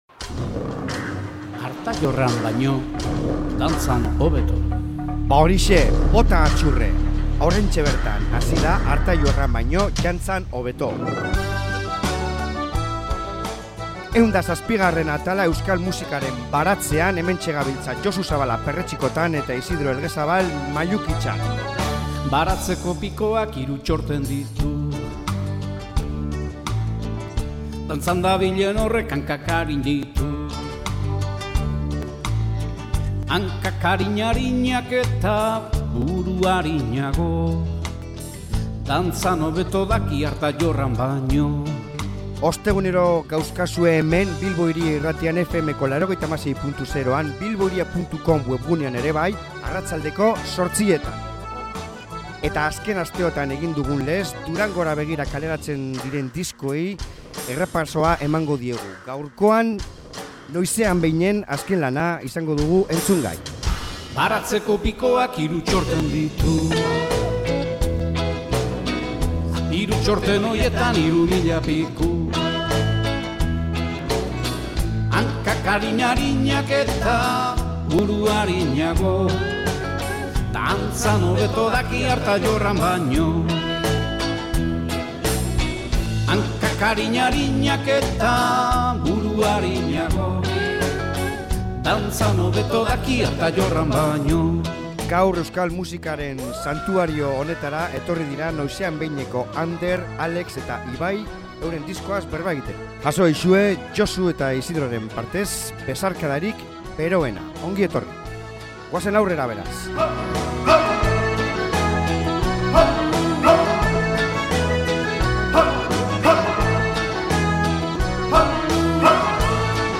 Metal estiloak eta melodia uztarketa fina egin dute